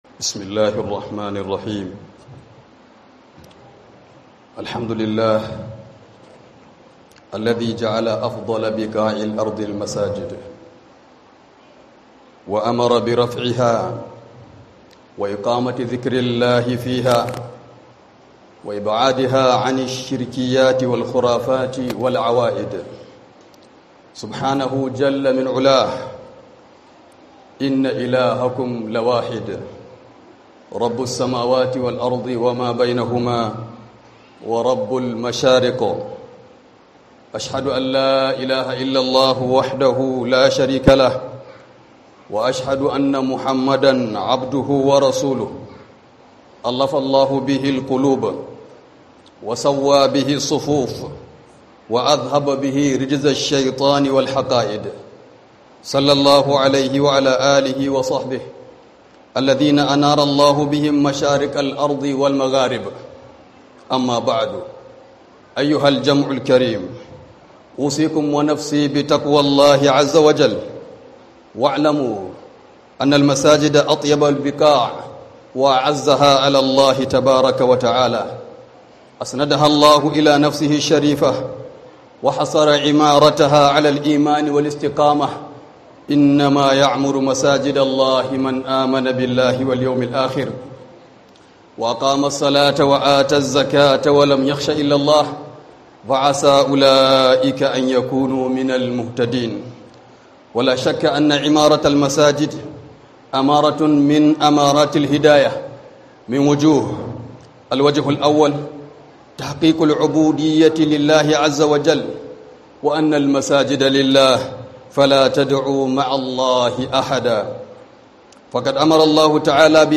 001 Muhimmancin Raya Masallaci a Addinin Musulunci - HUDUBA